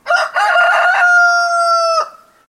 Rooster Efecto de Sonido Descargar
Rooster Botón de Sonido